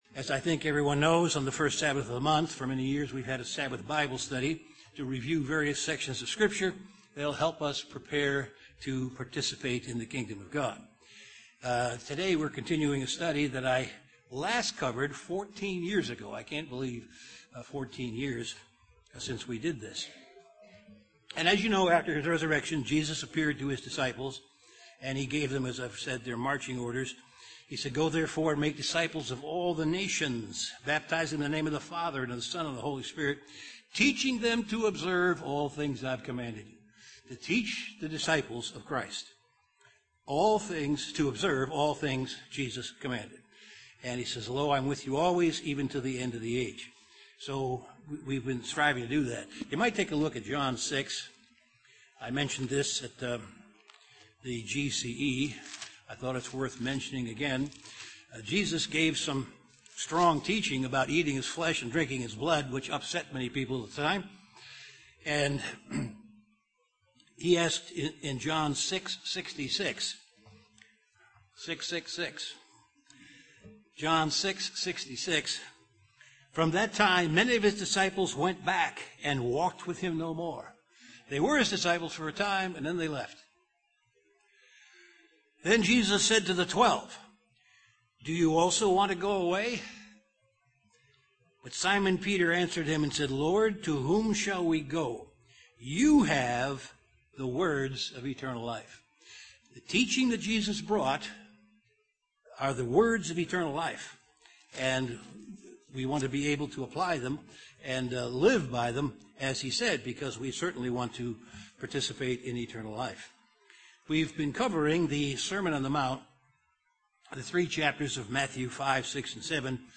Continuation of an in-depth Bible Study on the Sermon on the Mount. This study discusses murder and adultery.